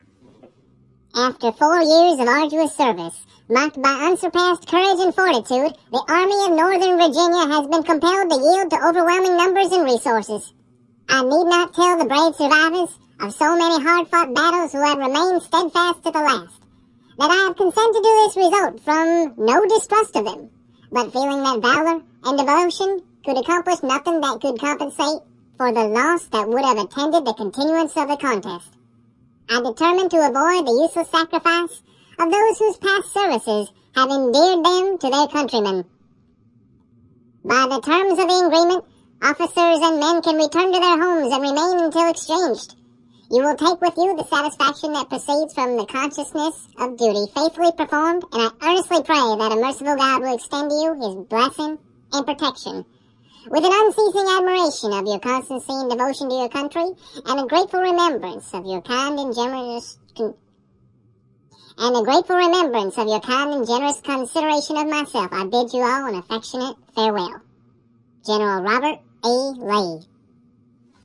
描述：R.E Lee将军著名的告别演说（投降）被记录下来，并被当作是由一只卡通花栗鼠说的。 录音是在Zoom H4n上进行的。使用MOTU Digital Performer中的Spectral Effects进行操纵
声道立体声